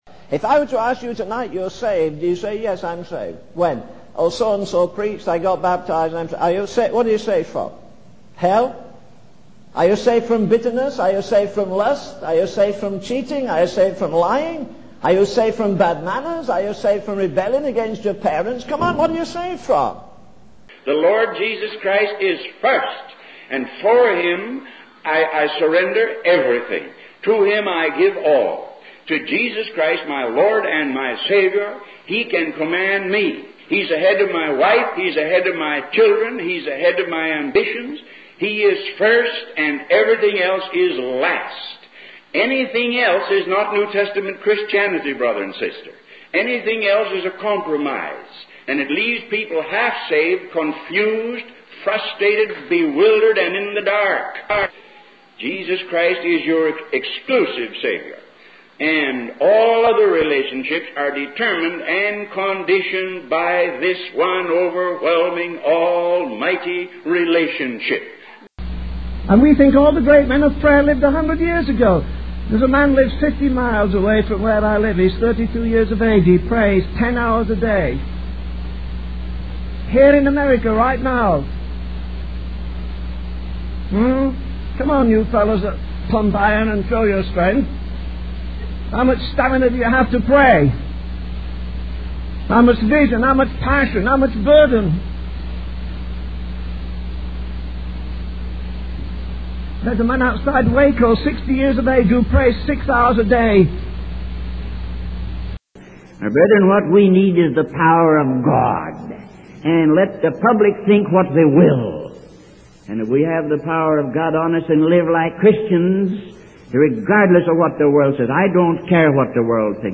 In this sermon, the preacher emphasizes the importance of focusing on one thing in the Christian life. He highlights the need to stay on the straight path towards God and not get sidetracked by worldly pursuits.